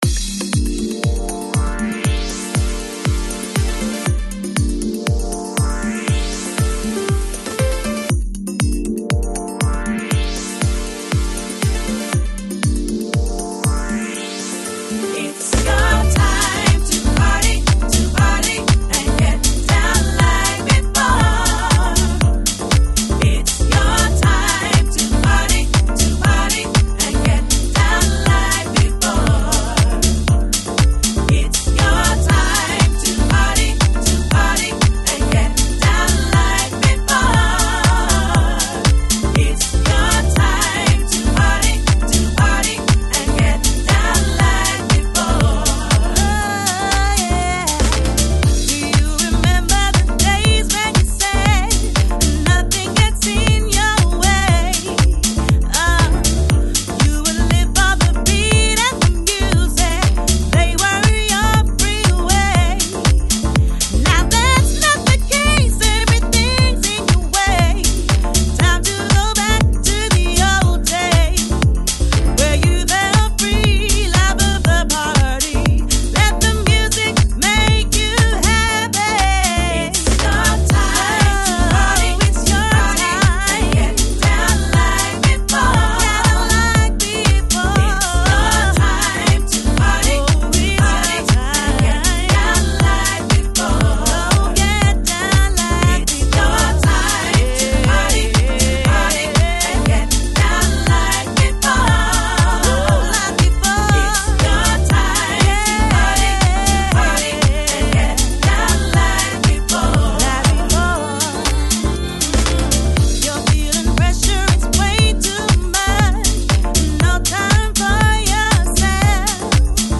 Жанр: Lounge, Chill Out, Soulful House, Deep House